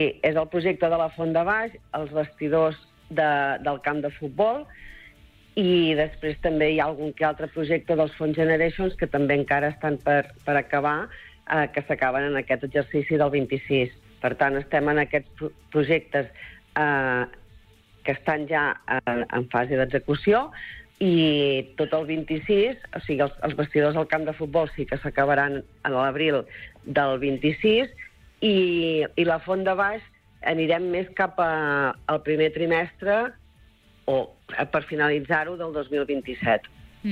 Entrevistes SupermatíSupermatí
En una entrevista al Supermatí, l’alcaldessa de Begur i Esclanyà, Maite Selva, ha valorat el tancament del 2025 i ha explicat les prioritats per a l’any vinent.